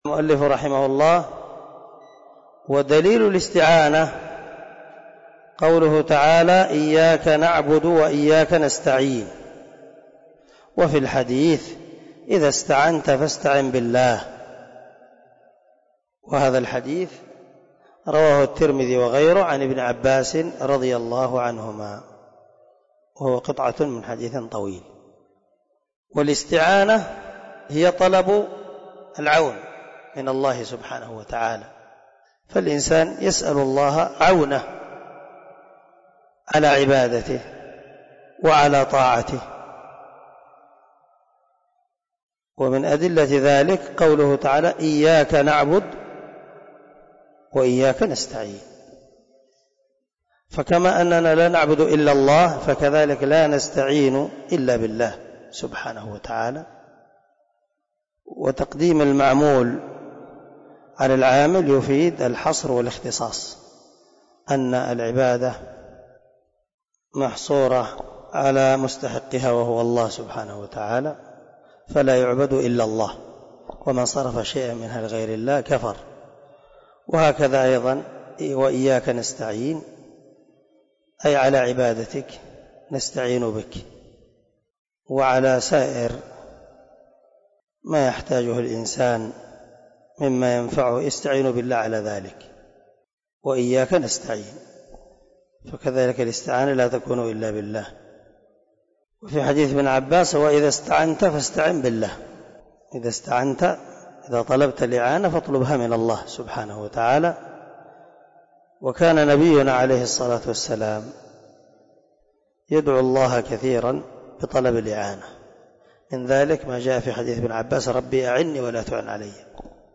🔊 الدرس 18 من شرح الأصول الثلاثة